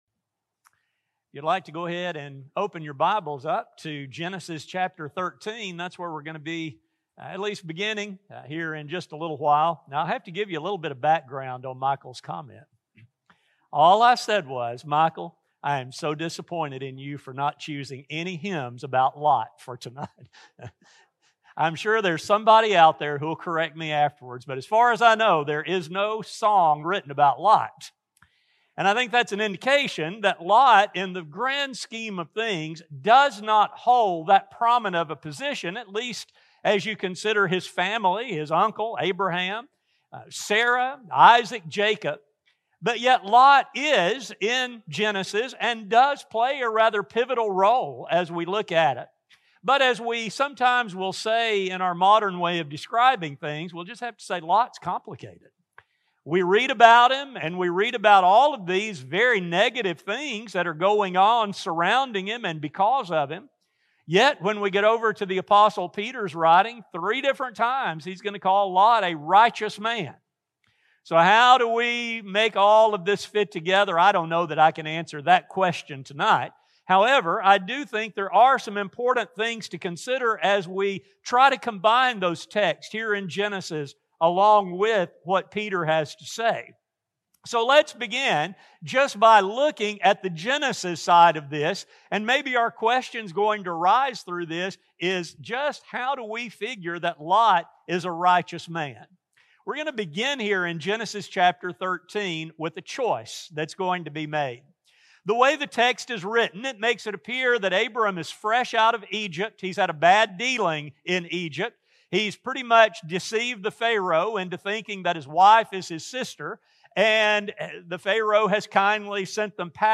A sermon recording
2022 at our evening worship service.